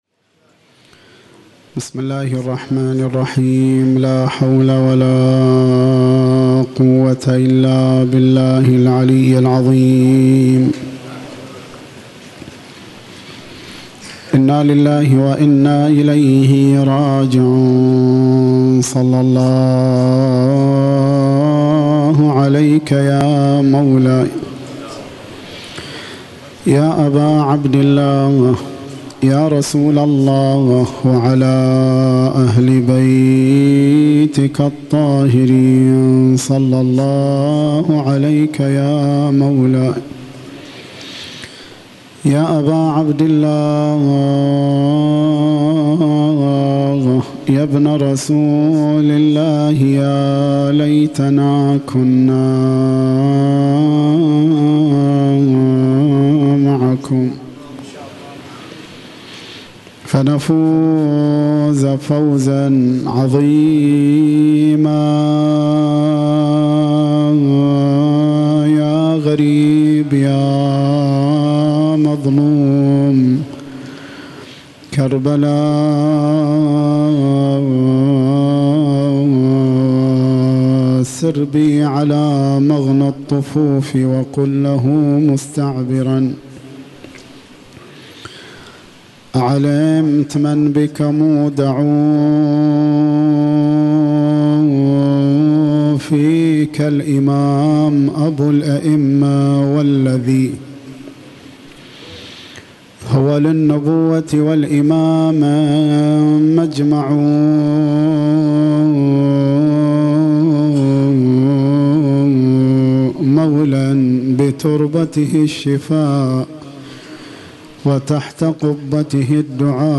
الليلة الحادي عشر : رثاء الإمام الحسين عليه السلام